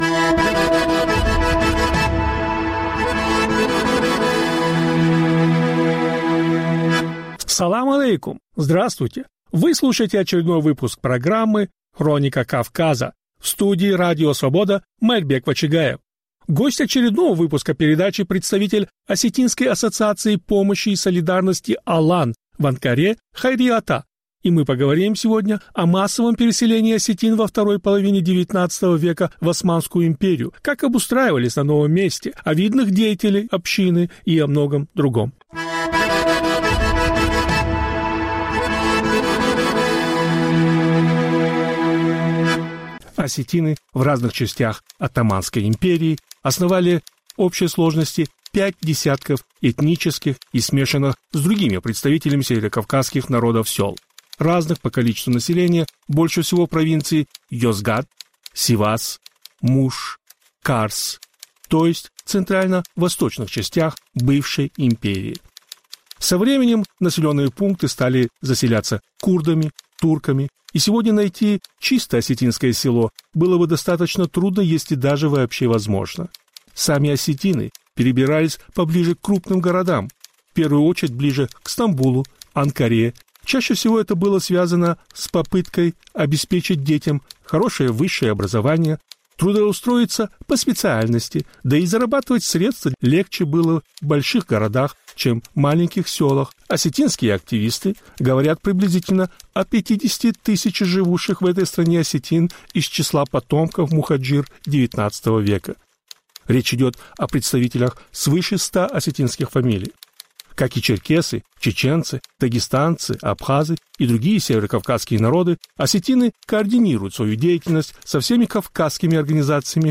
Гости очередного выпуска программы - осетинские активисты из Турции.